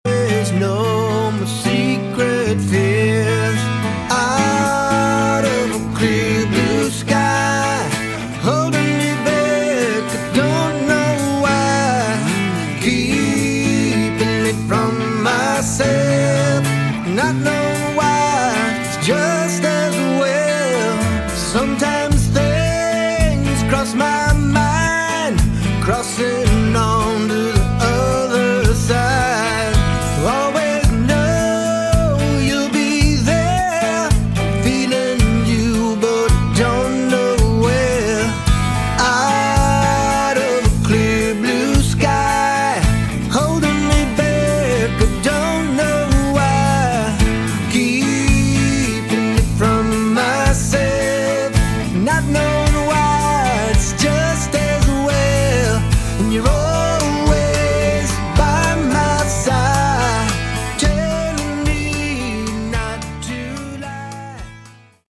Category: Melodic Rock
vocals, keyboards, guitar
guitar, bass, keyboards
drums
Modern (pop) rock.
Quite laid back at times.